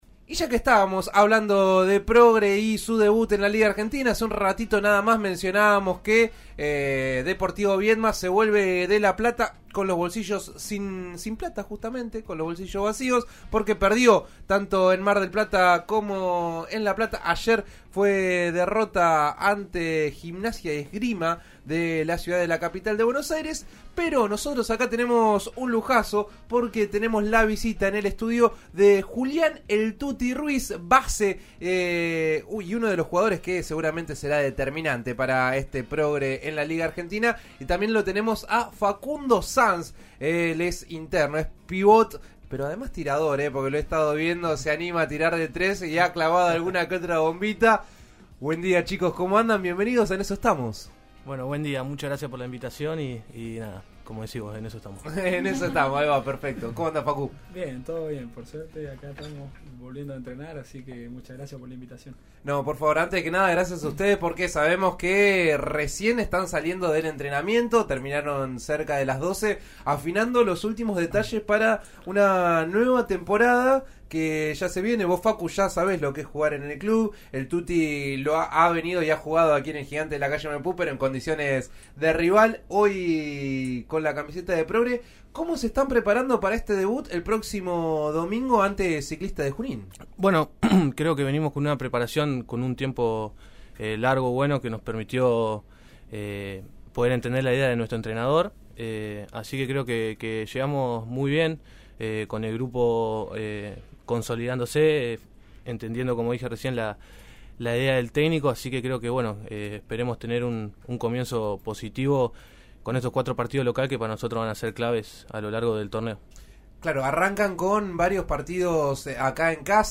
Visitaron a RN Radio y hablaron sobre las expectativas de cara a un nuevo inicio.